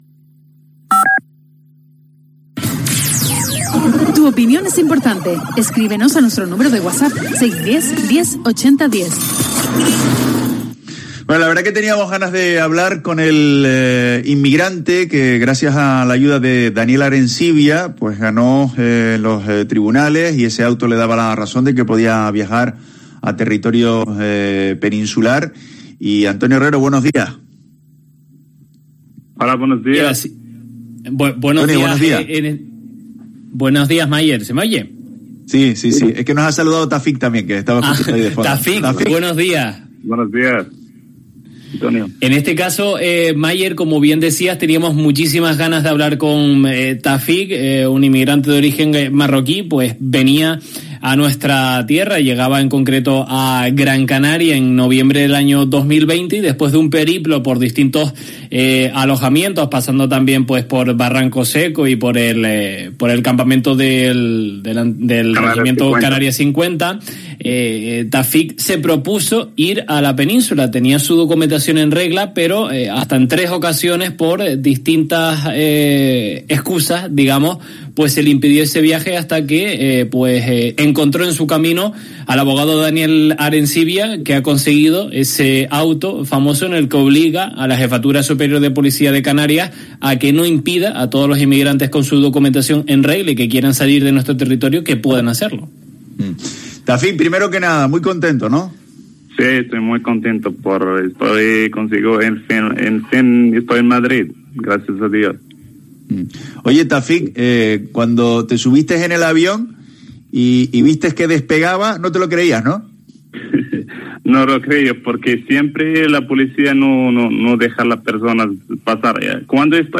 El propio migrante ha relatado en los micrófonos de COPE Canarias que su trayecto en patera fue “muy duro” .